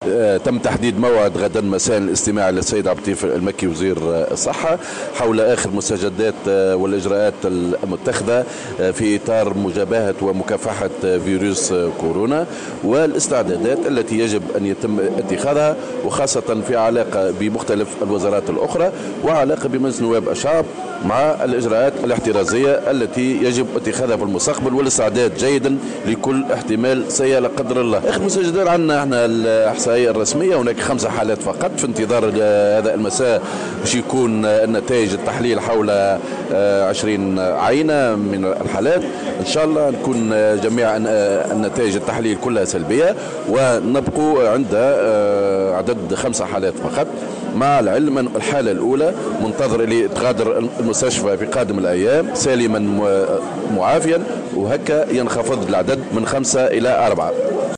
أكد اليوم الثلاثاء 10 مارس 2020 ،رئيس لجنة الصحة والشؤون الإجتماعية بمجلس نواب الشعب خالد الكريشي، في تصريح للجوهرة أف أم ،أن اللجنة ستستمع غدا لوزير الصحة عبد اللطيف المكي حول مستجدات فيروس كورونا وآخر التطورات المتعلقة بمجابهة ومكافحة هذا الفيروس.